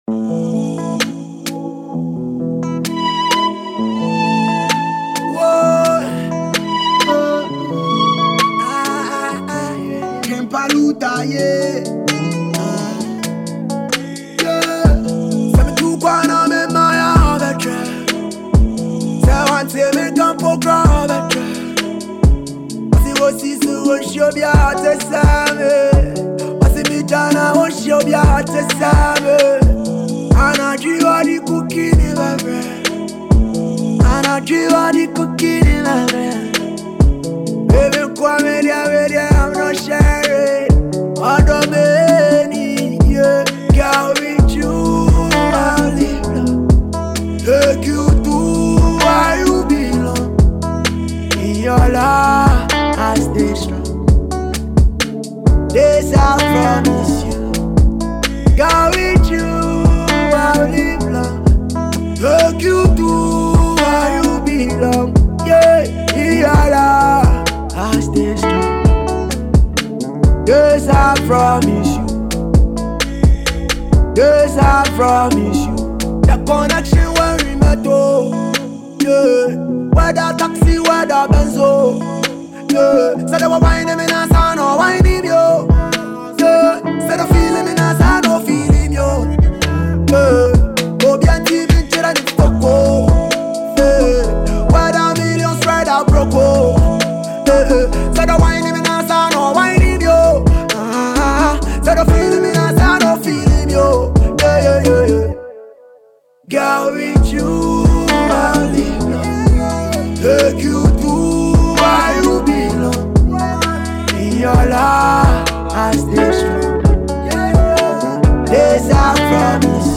a versatile singer and rapper
a solo track